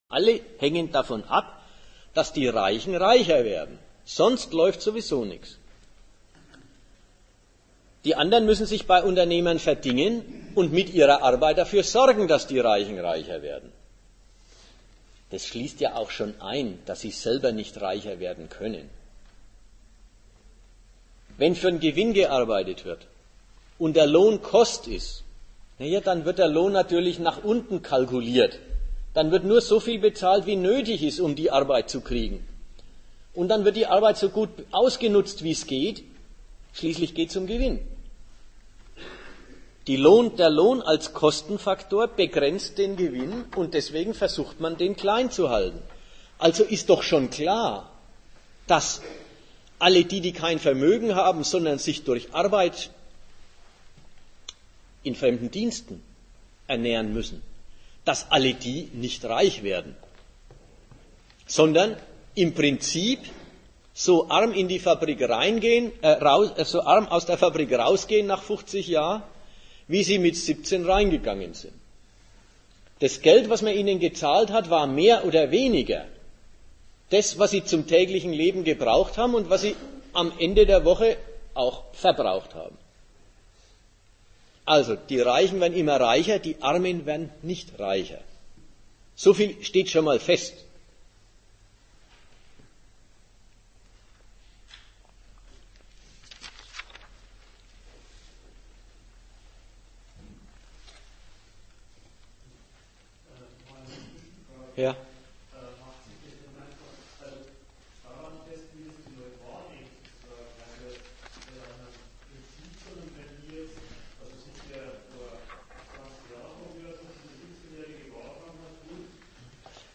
Ort Nürnberg
Dozent Gastreferenten der Zeitschrift GegenStandpunkt